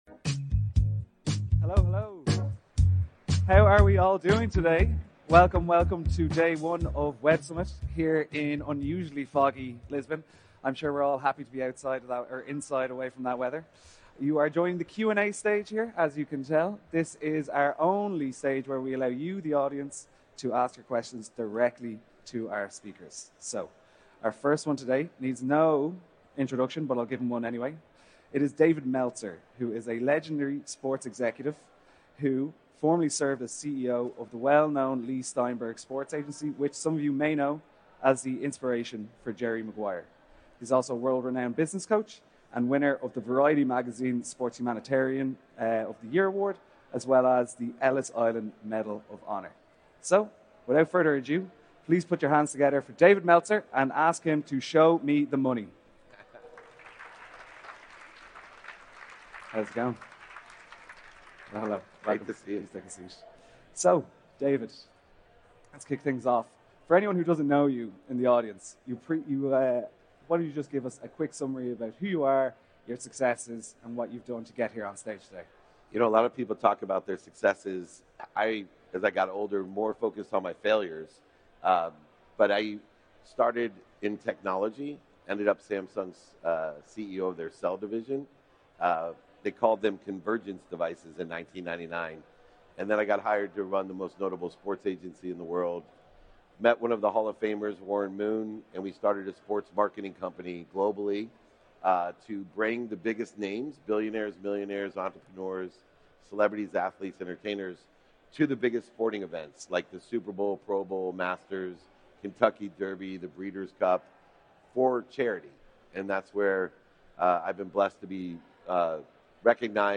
Today's episode features an engaging live Q&A session from Web Summit's Ask Me Anything panel.